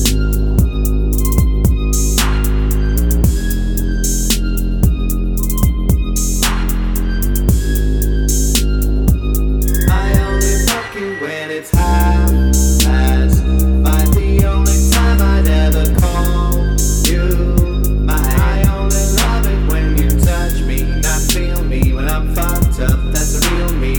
clean version Pop